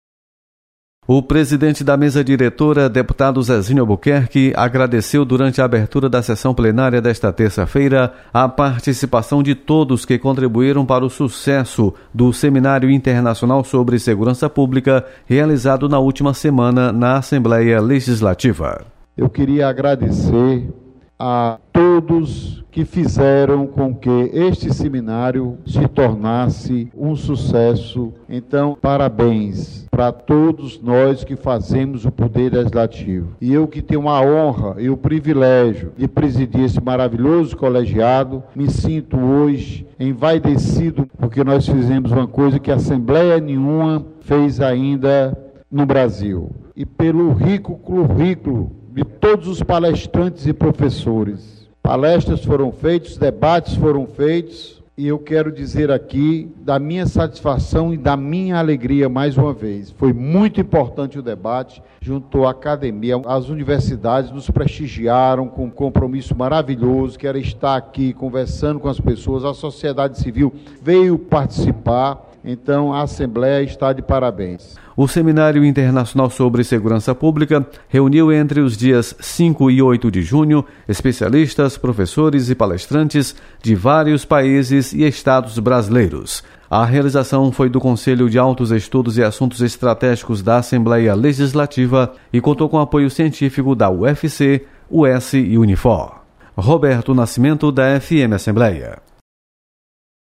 Deputado Zezinho Albuquerque destaca sucesso do Seminário Sobre Segurança.